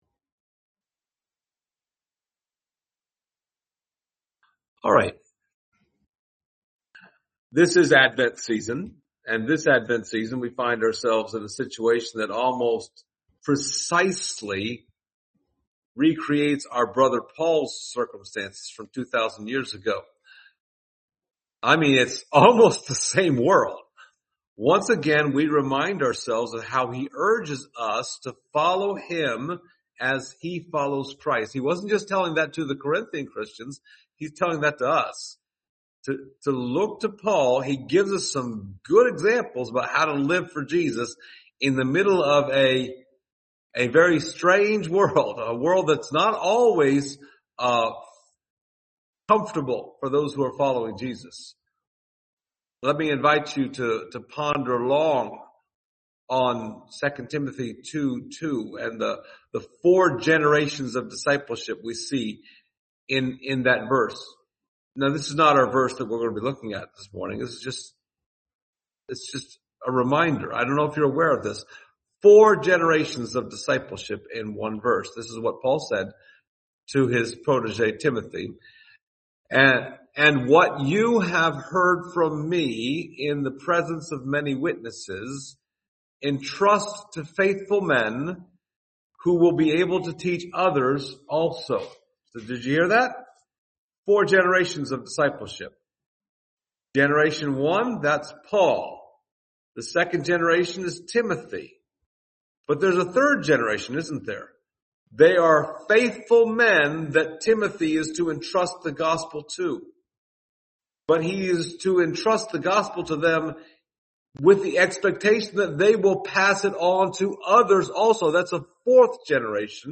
Acts 28:11-31 Service Type: Sunday Morning Topics: diversity , paganism , religious tension « Snakehandlers?